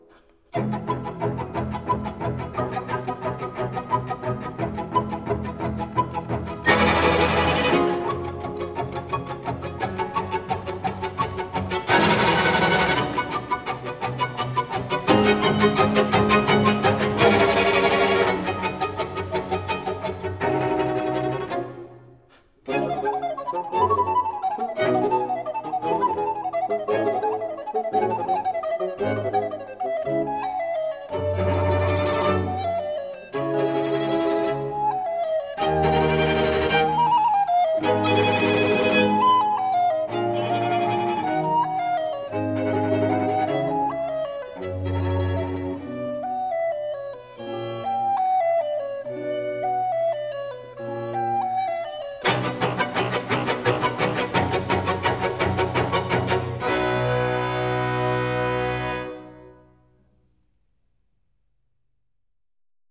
hegedűre és zenekarra